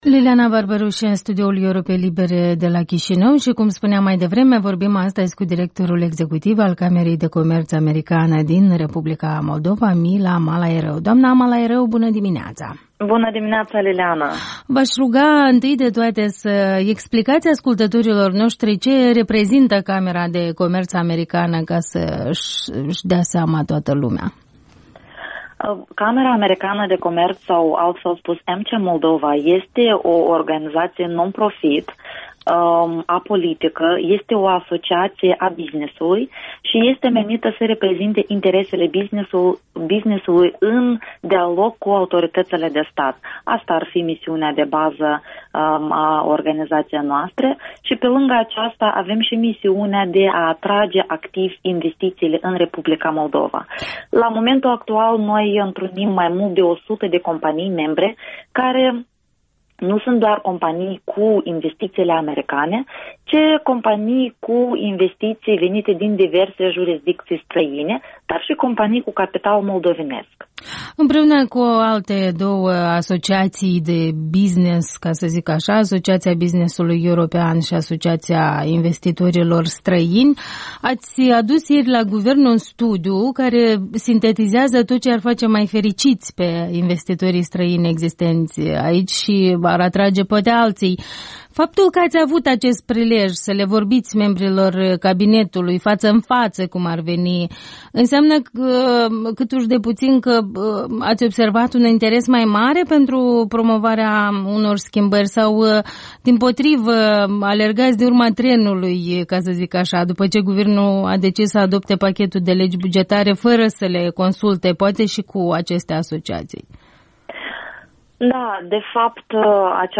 Interviul dimineţii